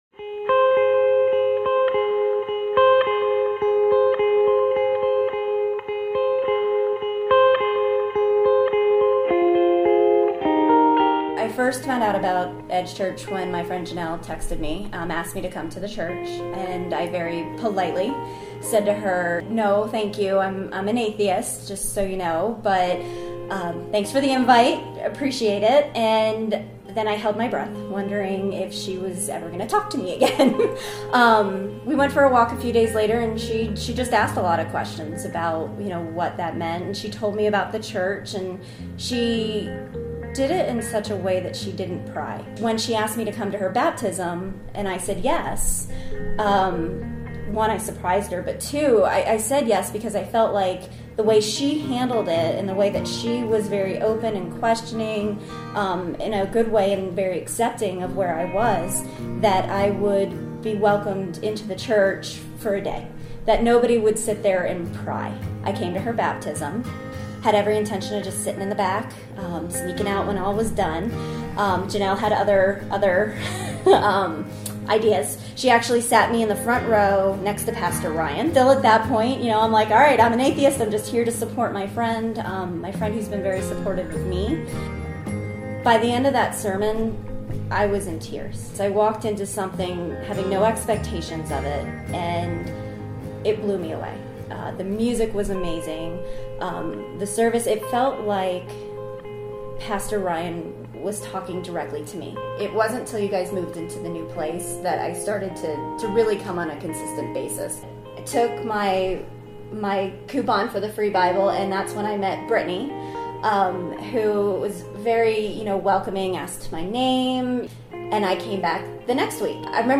Power: Experiencing The Power of Prayer: 1 Kings 18:16-46 & James 5:16-18 – Sermon Sidekick